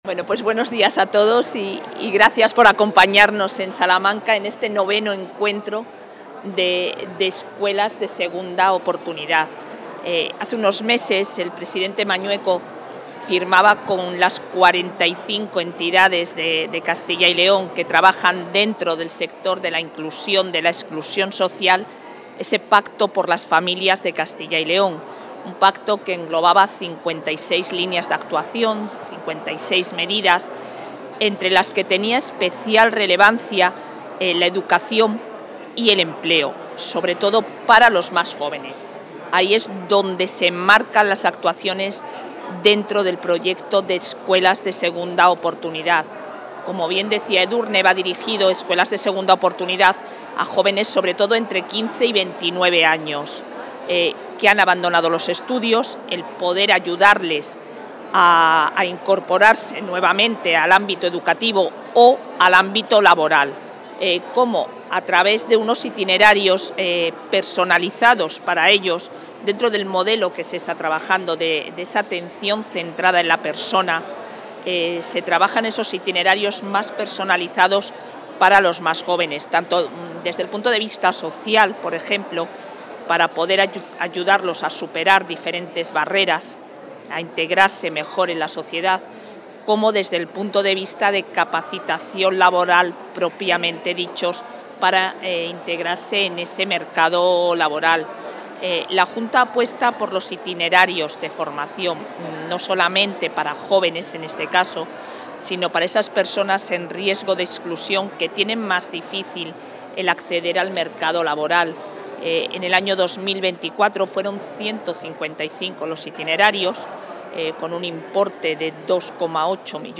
Intervención de la vicepresidenta.
La vicepresidenta y consejera de Familia e Igualdad de Oportunidades, Isabel Blanco, ha inaugurado hoy en Salamanca el IX Encuentro Nacional de Escuelas de Segunda Oportunidad, donde ha manifestado de manera específica el compromiso del Ejecutivo autonómico con la empleabilidad de los jóvenes en situación de dificultad junto a Escuelas Pías Santiago Uno y Fundación Juan Soñador, a través de ese modelo de formación para la inserción laboral.